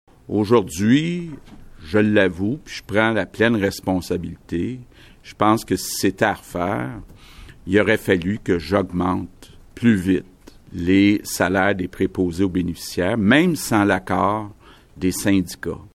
Après avoir dévoilé ces chiffres, le premier ministre François Legault est revenu, dans son point de presse quotidien,  sur la situation dans les CHSLD.